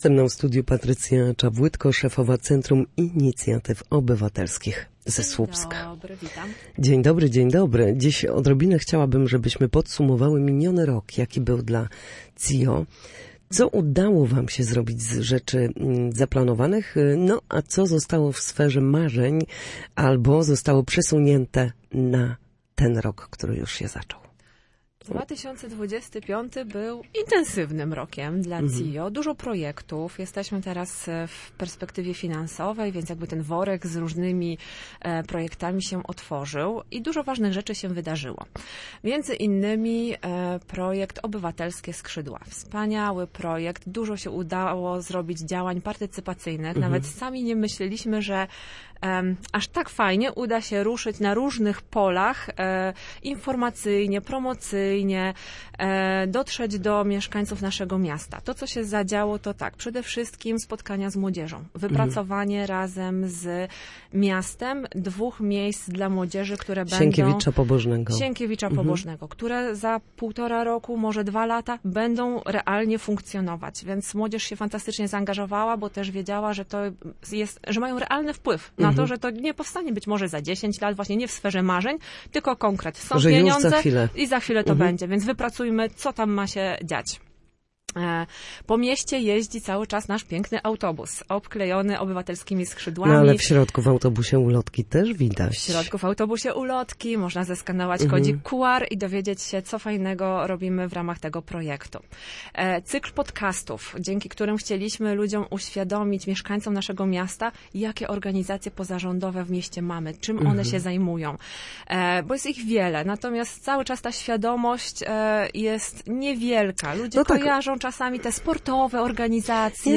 Na naszej antenie podsumowała miniony rok, mówiła także o planach na najbliższe miesiące.